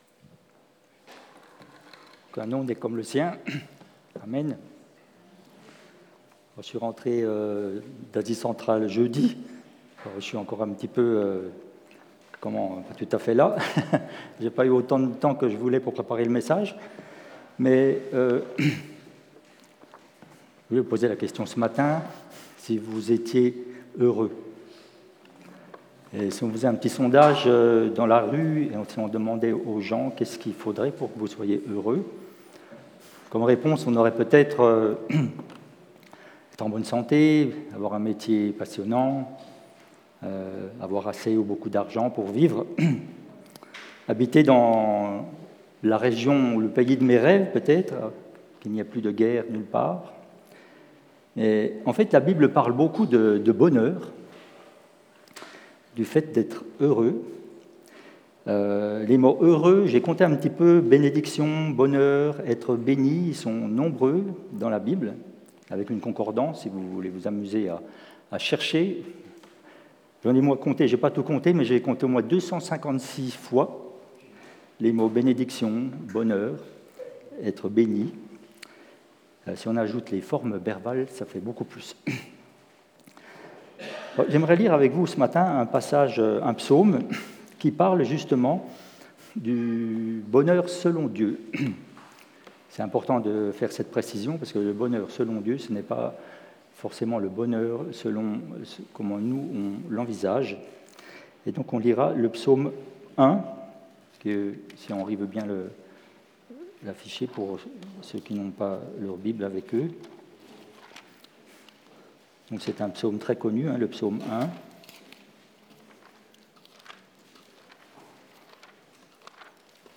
Culte du dimanche 20 Juillet 25
Prédications